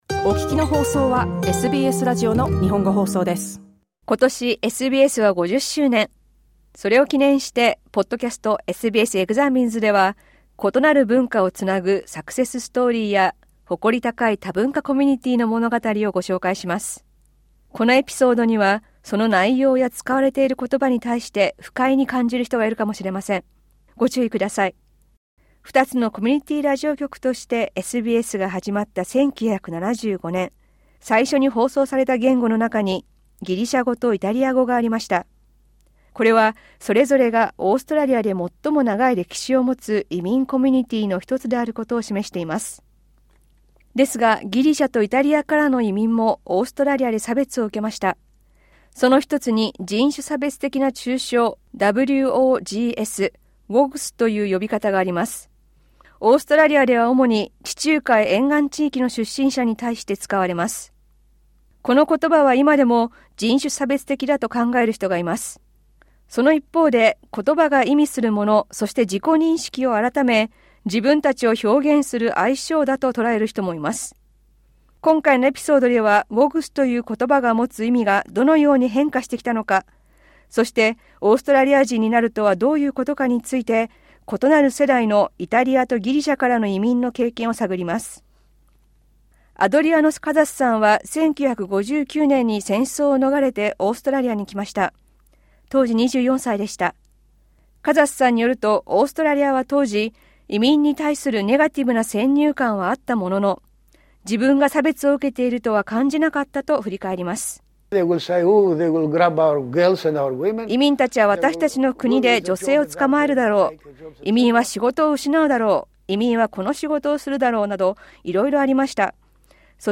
These Greek and Italian migrants share their experiences of Australia and their place within it.